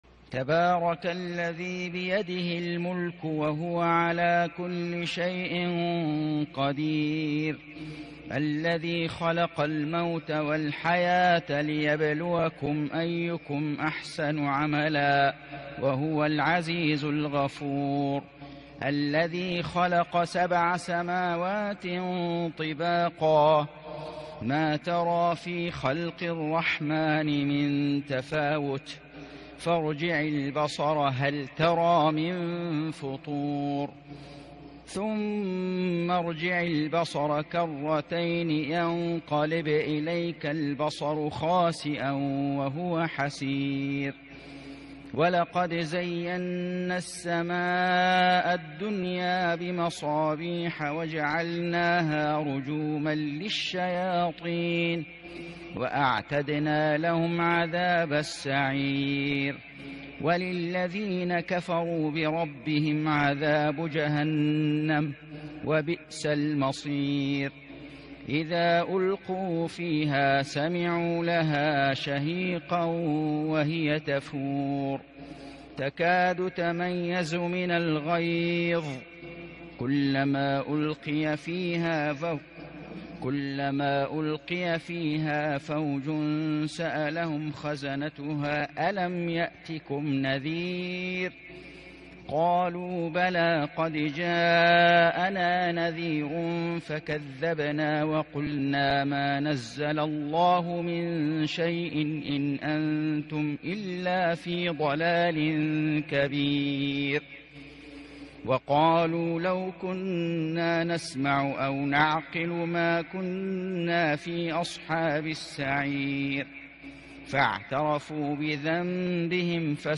سورة الملك > السور المكتملة للشيخ فيصل غزاوي من الحرم المكي 🕋 > السور المكتملة 🕋 > المزيد - تلاوات الحرمين